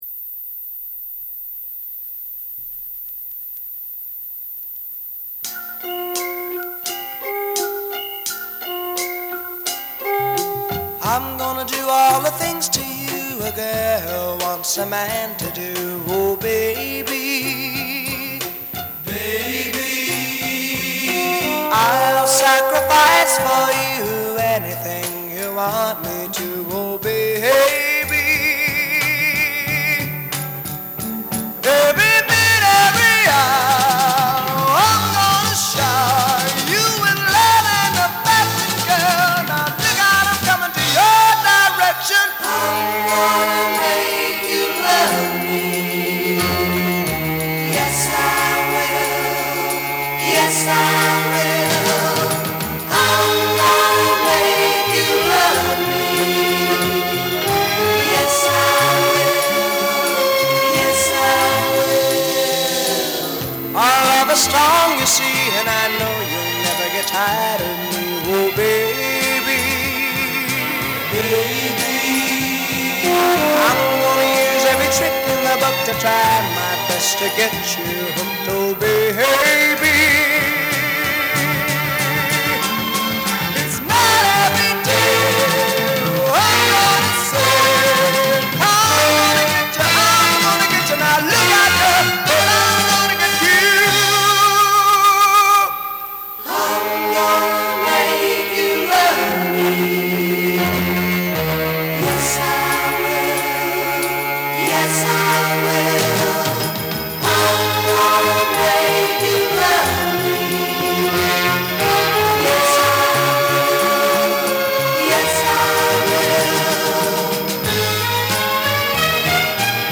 Went For Baroque On This Version